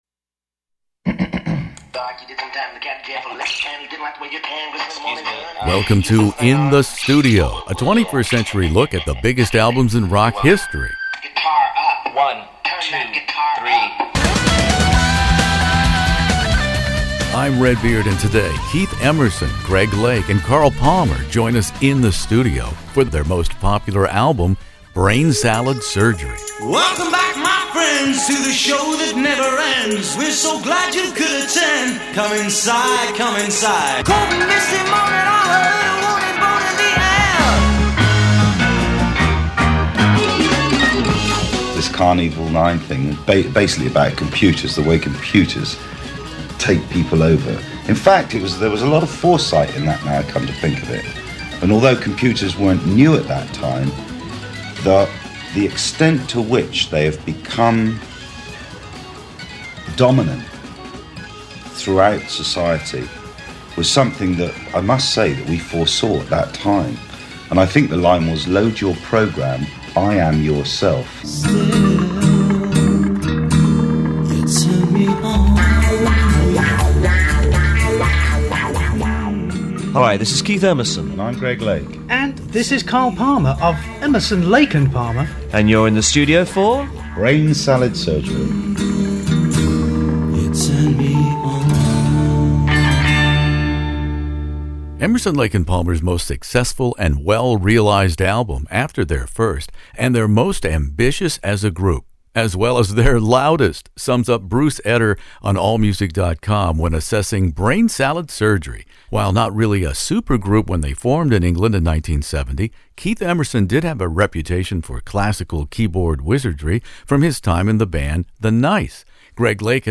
One of the world's largest classic rock interview archives, from ACDC to ZZ Top, by award-winning radio personality Redbeard.
It is the golden anniversary of that album which came out at the apex of the Progressive Rock era, and here In the Studio we have my archival interviews with the late keyboard whiz Keith Emerson, the choirboy-voiced late Greg Lake, and the bombastic drummer Carl Palmer.